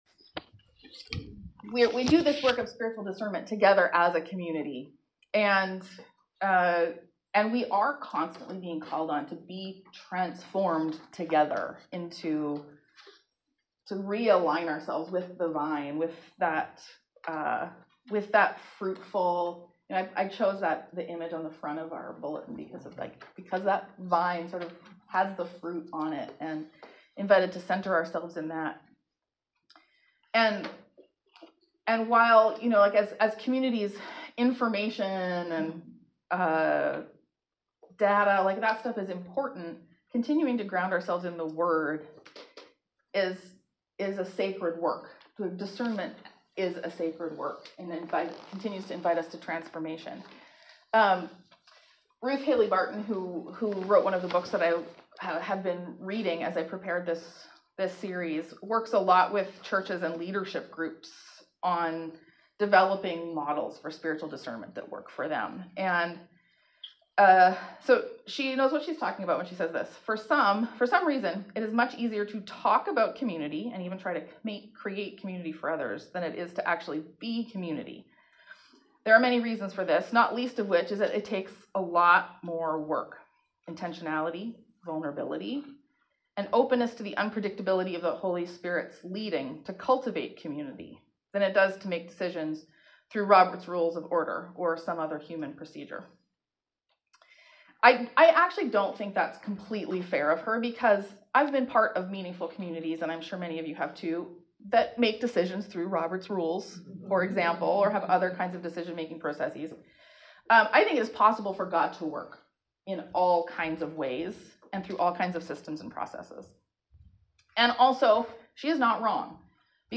The recording of this sermon sounds a little bit like it picks up in the middle of something because it was preceded by the Spiritual Practice of dwelling in the word (described below). It also captures some of the discussion at the end of the preached word; the final five minutes or so of the recording is conversation and response. The audio on that portion may be more difficult to hear as not all voices were near to the microphone.